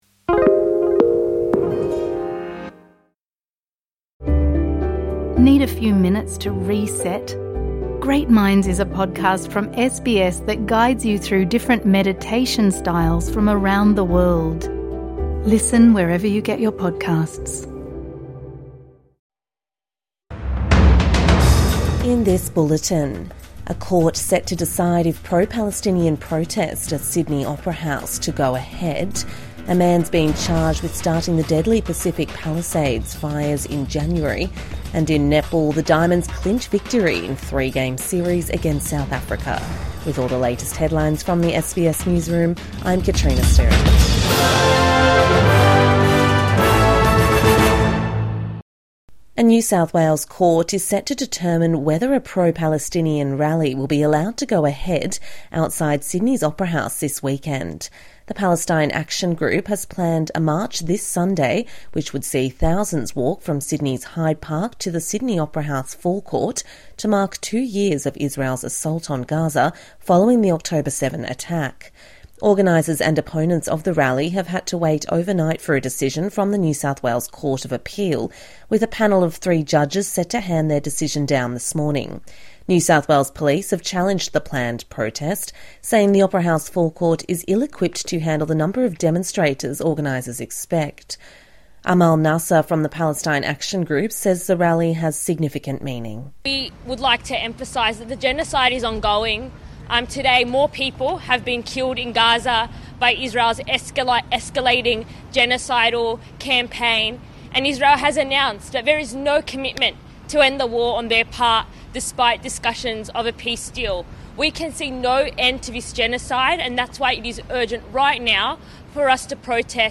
Court to decide on pro-Palestinian protest at Sydney Opera House | Morning News Bulletin 9 October 2025